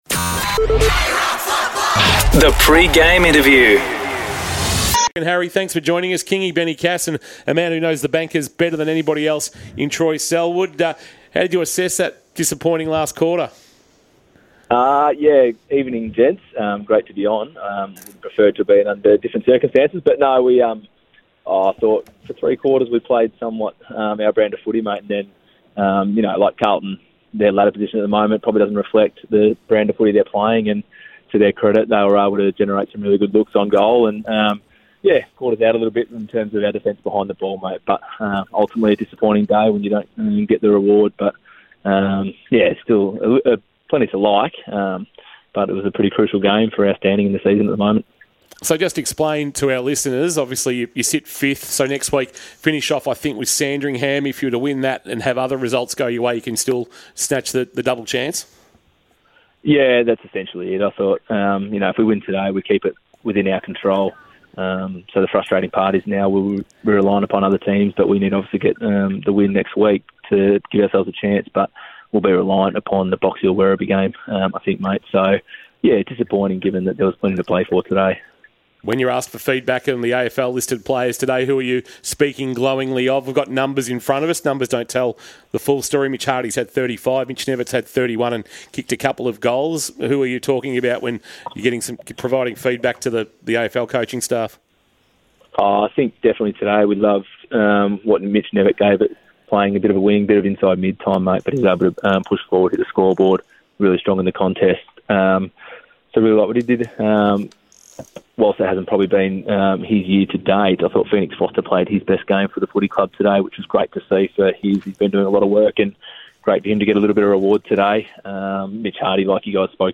Pre-match interview